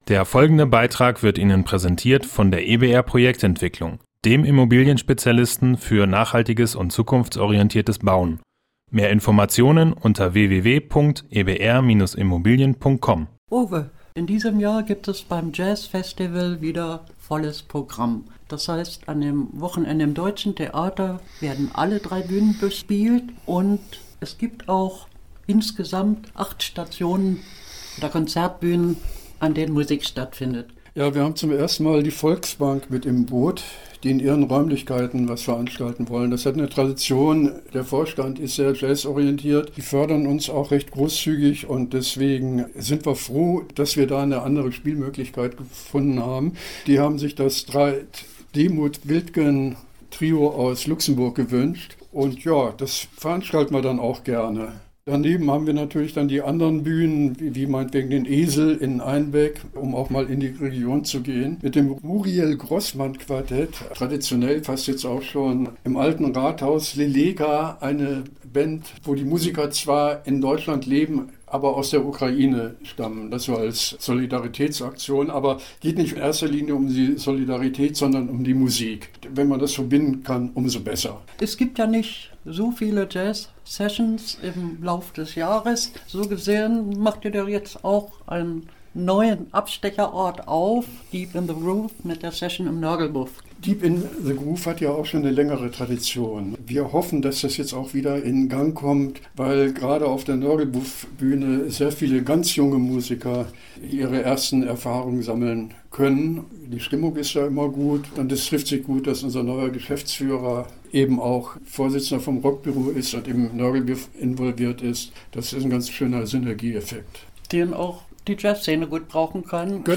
Beiträge > Das Programm zum 45. Göttinger Jazzfestival – Gespräch